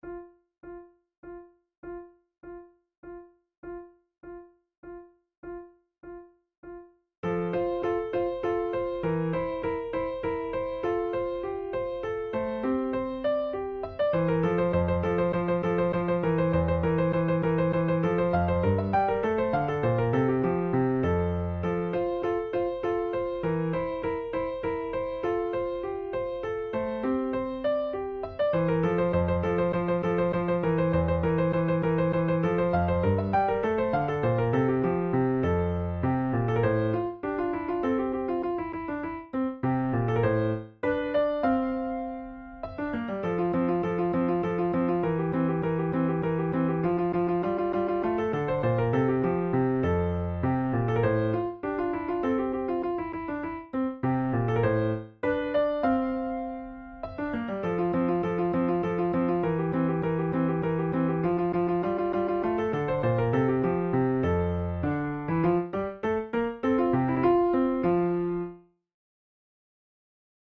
InstrumentationFlute and Piano
KeyF major
Time signature3/4
Tempo100 BPM
transcribed for flute and piano